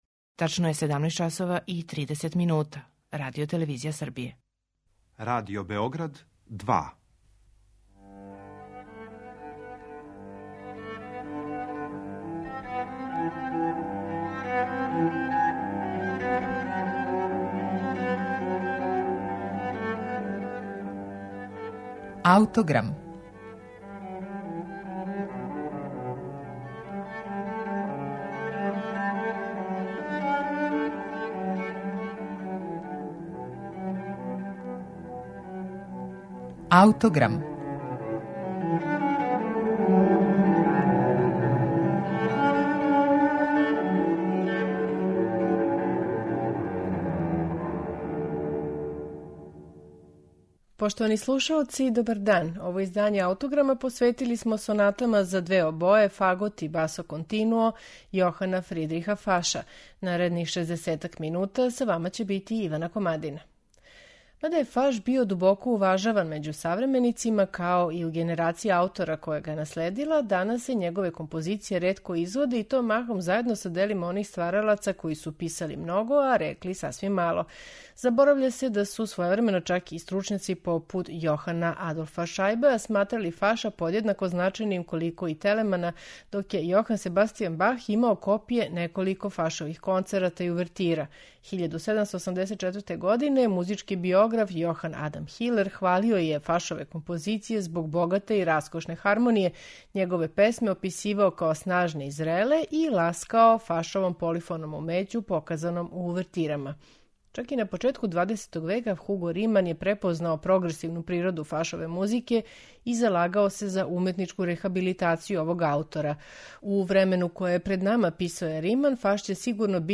Сонате за две обое, фагот и басо континуо Јохана Фридриха Фаша